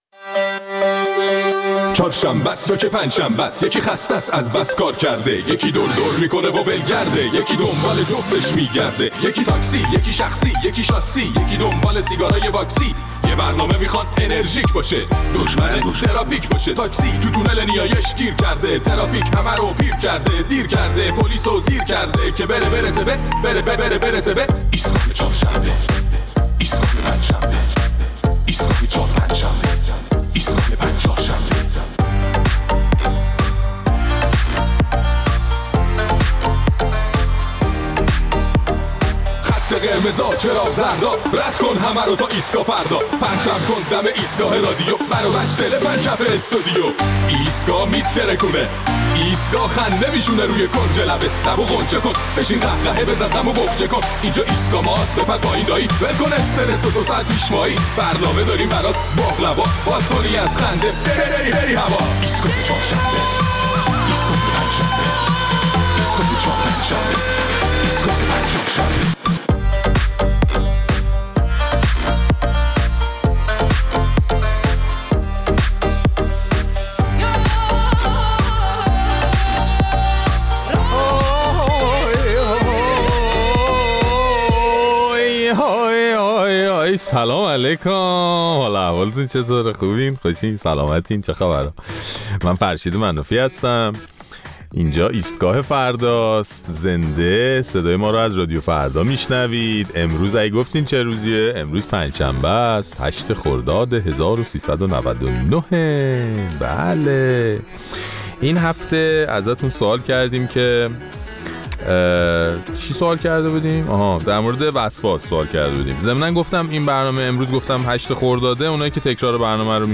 در این برنامه نظرات ادامه نظرات شنوندگان ایستگاه فردا را در مورد وسواس و انواع آن در دوران پساکرونا می‌‌شنویم.